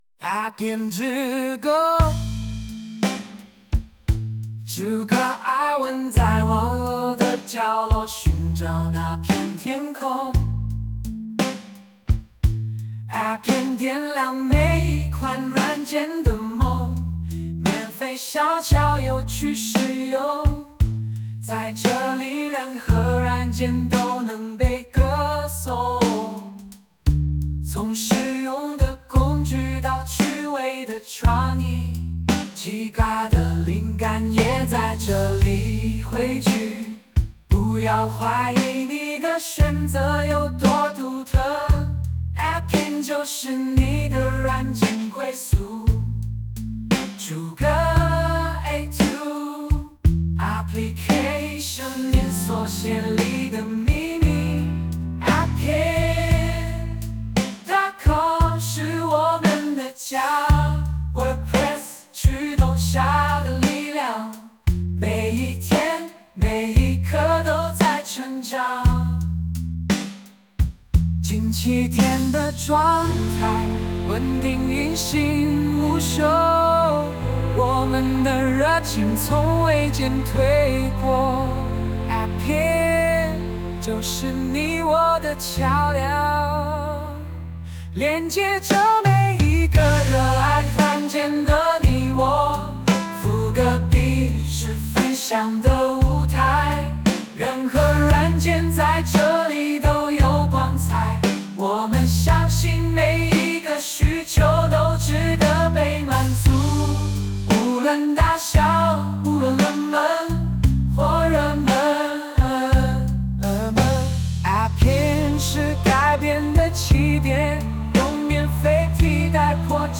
第二首旋律意外的很棒啊 :melting_face:
第二首音色有点像王力宏